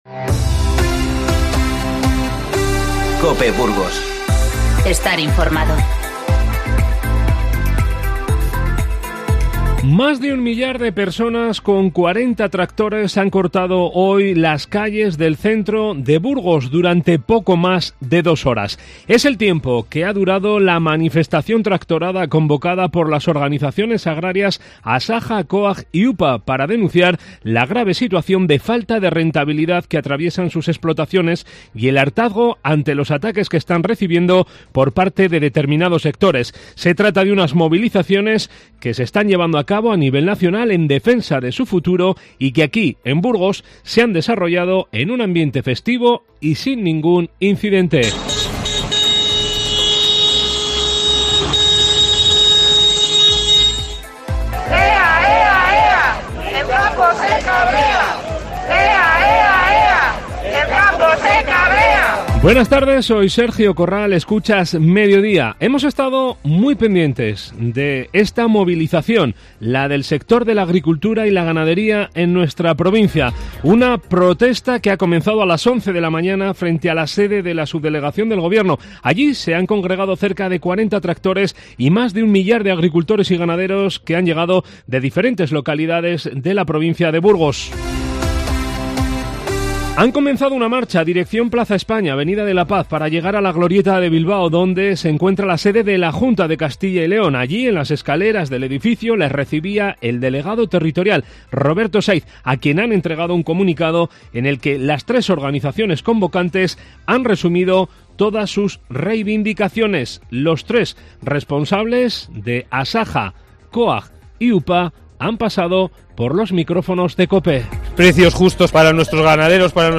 Informativo 30-01-20